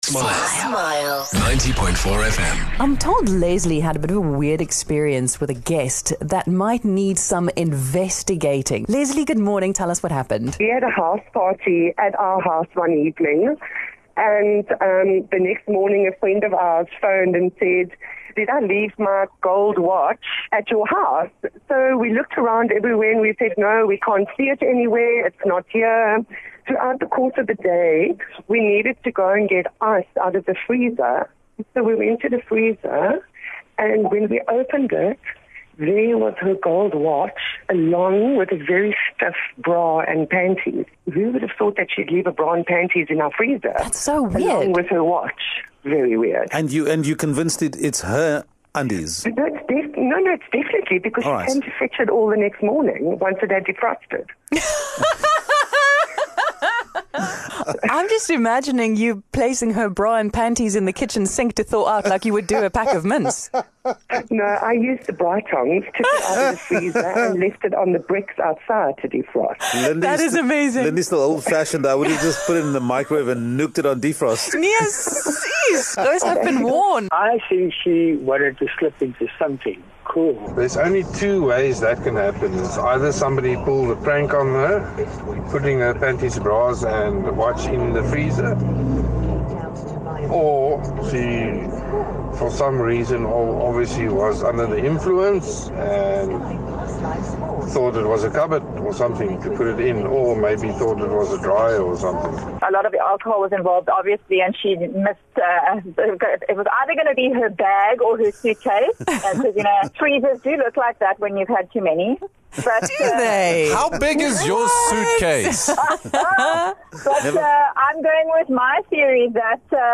In a conversation about the unusual behaviour of house guests Smile Breakfast got this call from someone about a bra and panty in her deep freeze. Listeners weighed in on how they might have gotten there.